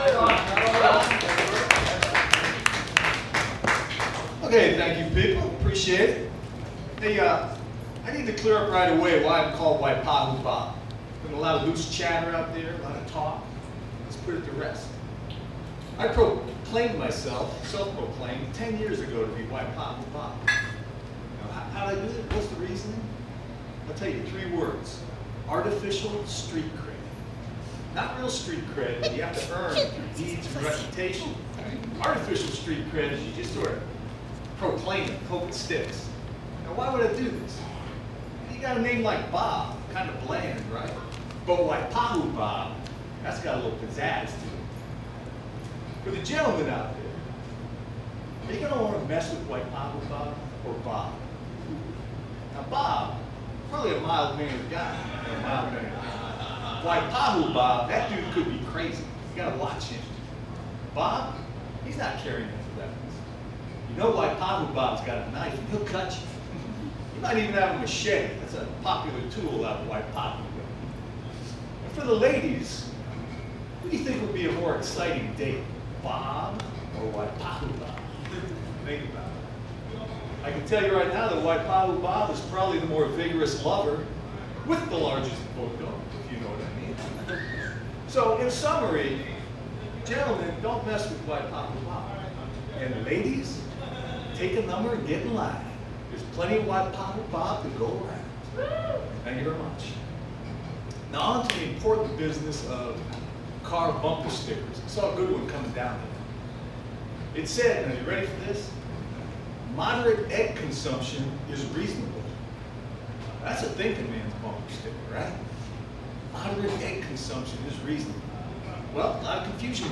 Stand-Up Comedy – Open Mic at the Downbeat Lounge – 12 Nov 2019